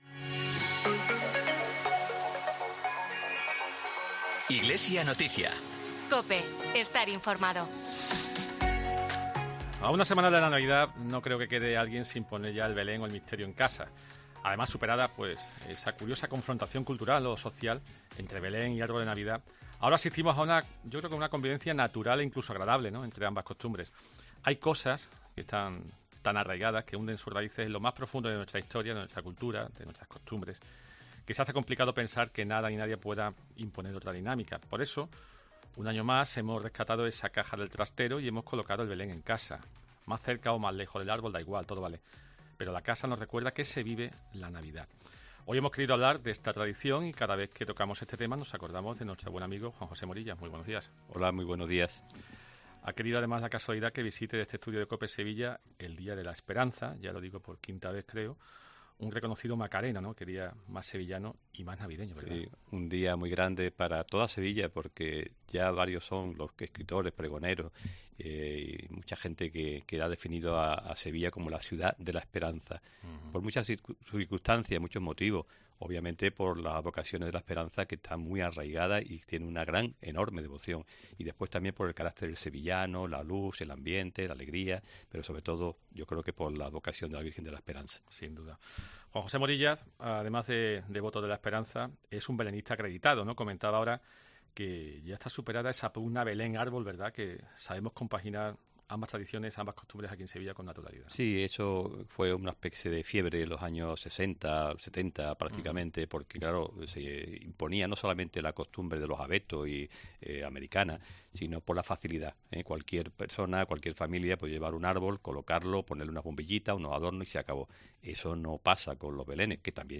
IGLESIA NOTICIA | Entrevista